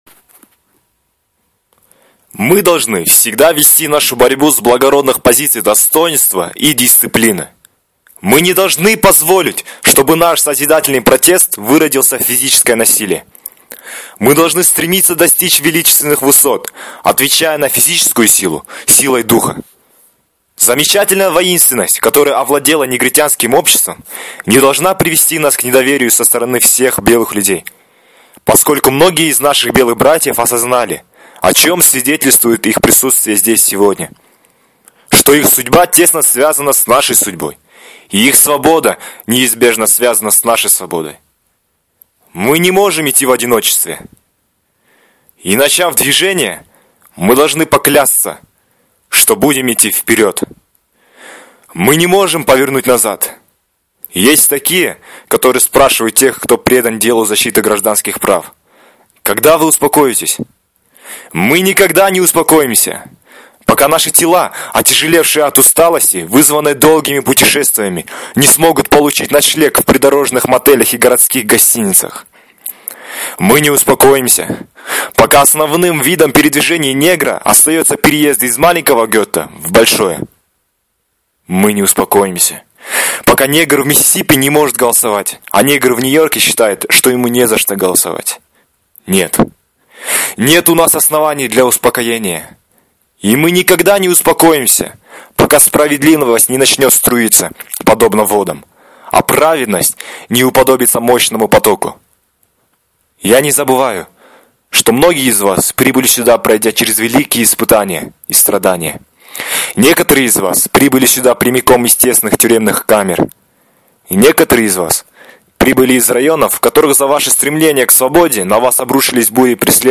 Аудиофайл представлен на конкурс "iDream" в честь 50-летия речи Мартина Лютера Кинга "У меня есть мечта". Для участия в конкурсе необходимо было записать отрывок из речи Мартина Лютера Кинга «У меня есть мечта» на кыргызском, русском или английском языке.